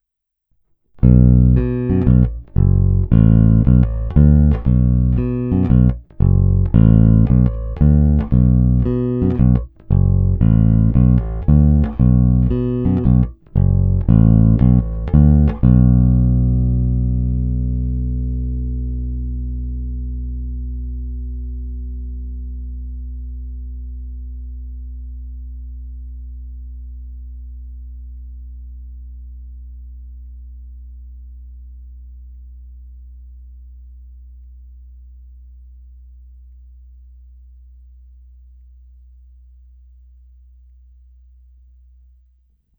Neskutečně pevný, zvonivý, s těmi správnými středy, co tmelí kapelní zvuk, ale při kterých se basa i prosadí.
Není-li uvedeno jinak, následující nahrávky jsou provedeny rovnou do zvukové karty, jen normalizovány, jinak ponechány bez úprav.
Hra nad snímačem